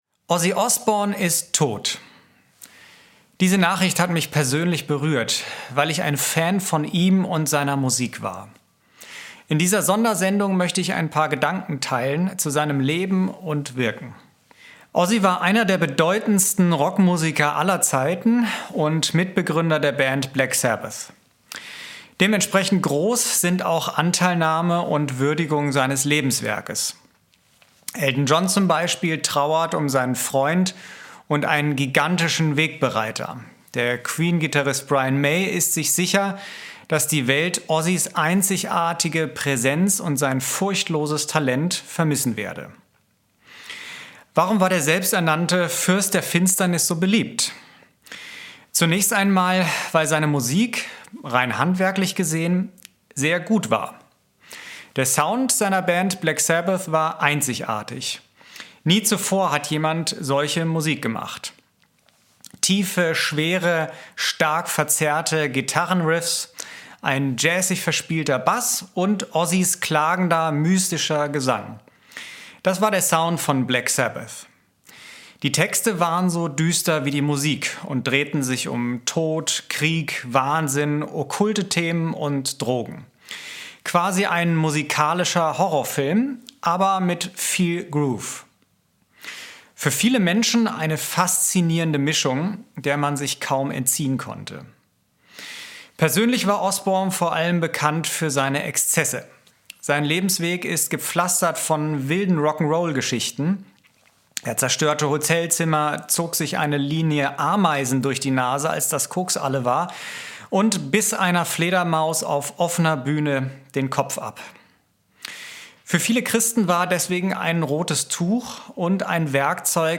Kategorie News